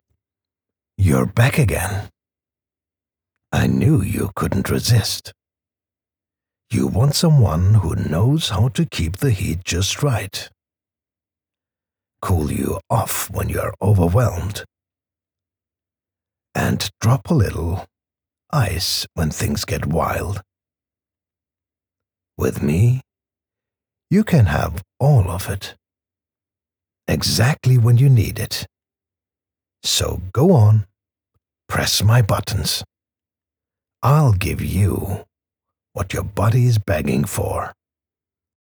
Character, Cartoon and Animation Voice Overs
Adult (30-50)